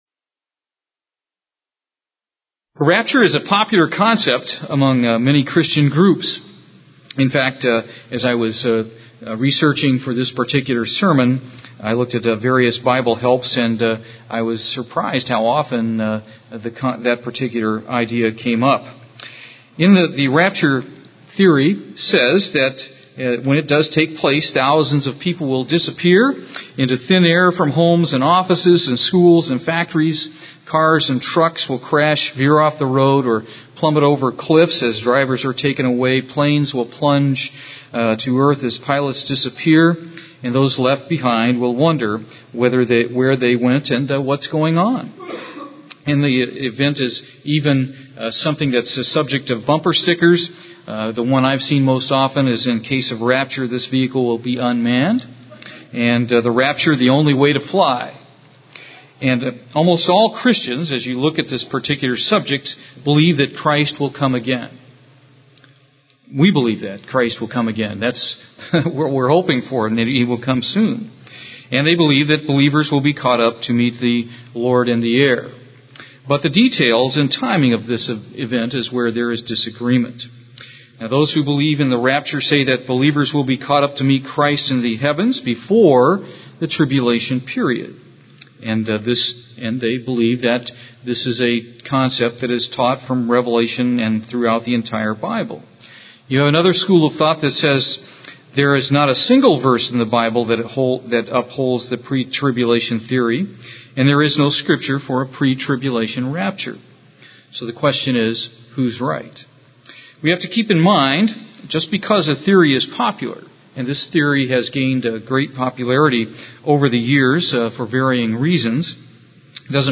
Given in Houston, TX
He first covers its history and then asks and answers the question, is it Biblical? UCG Sermon Studying the bible?